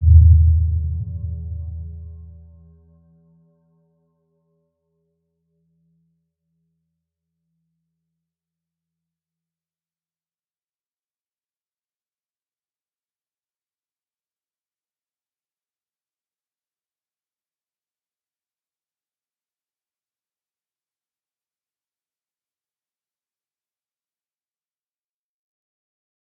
Round-Bell-E2-p.wav